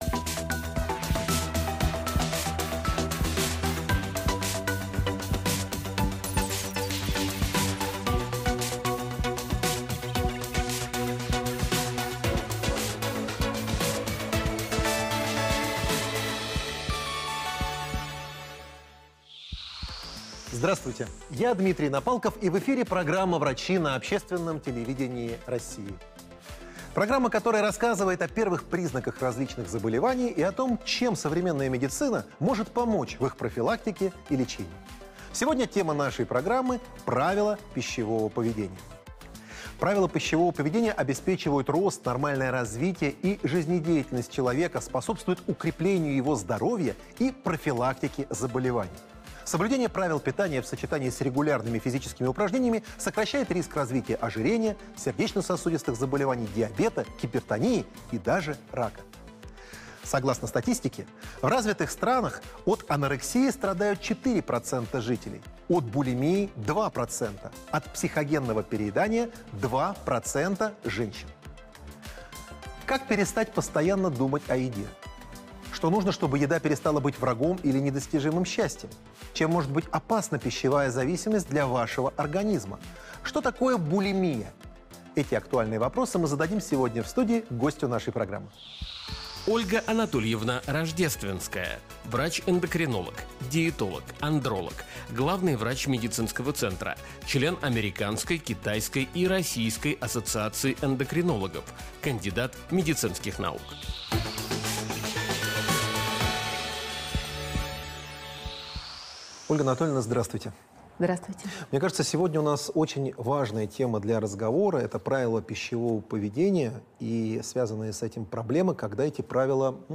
Как правильно питаться Дата: 08.09.2021 Источник: Общественное телевидение России Участники: проф.